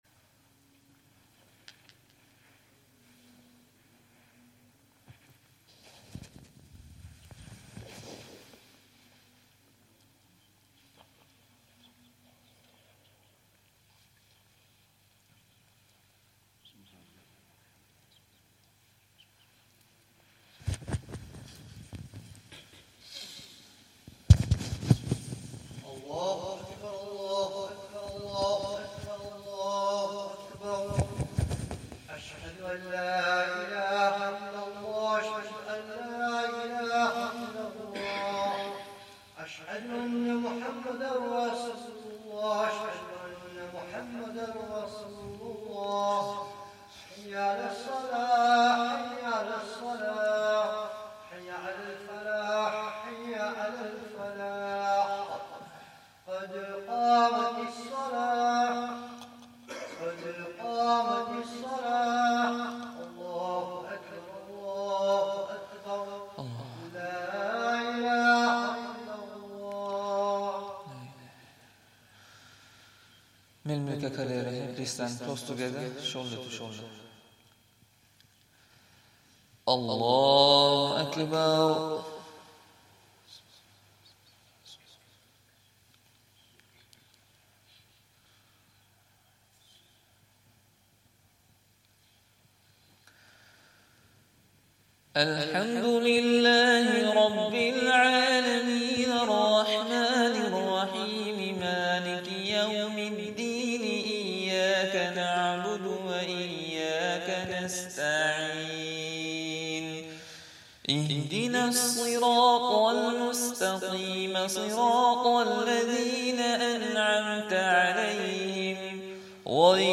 Fajr
Zakariyya Jaam'e Masjid, Bolton